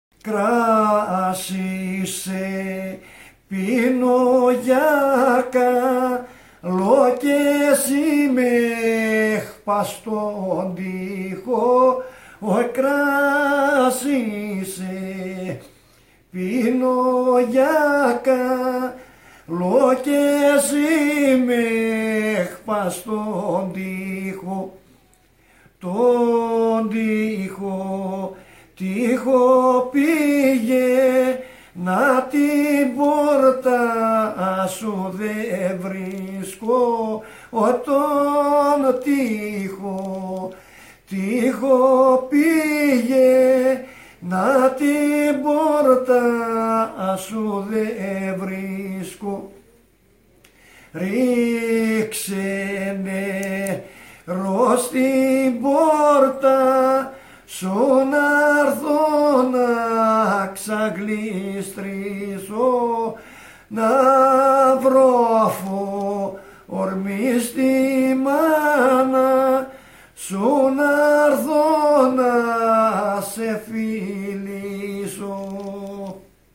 Δημοτικά τραγούδια από το Βυθό Βοΐου Κοζάνης.